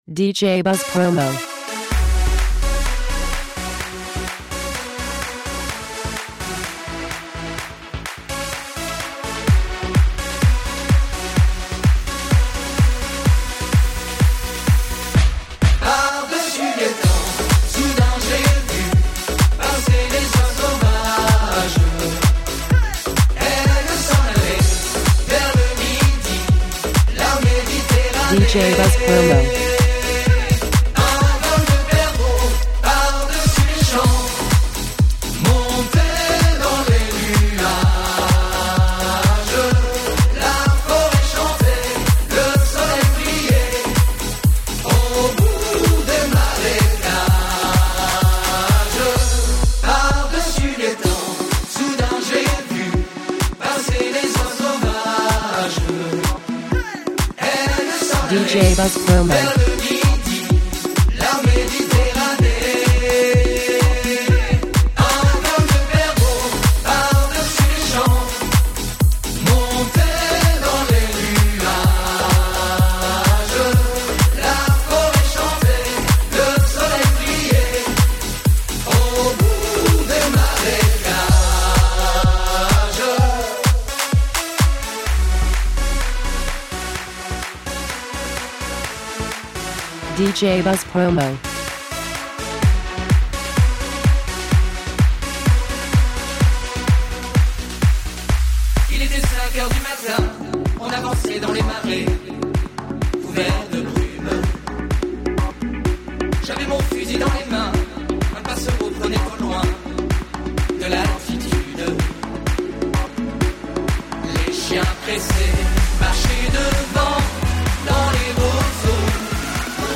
high-energy track